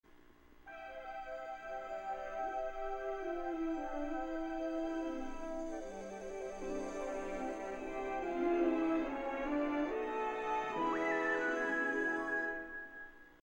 VHS intro
late 80s and early 2000s is such a nostalgic sound for me.